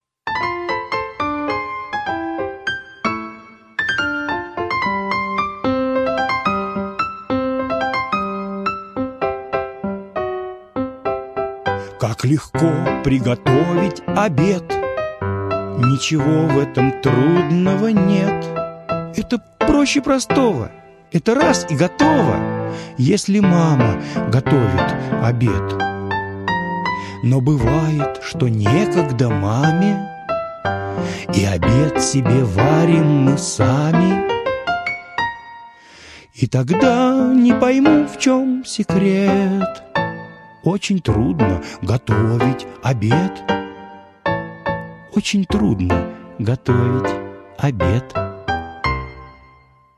Повара - аудио стих Заходера - слушать онлайн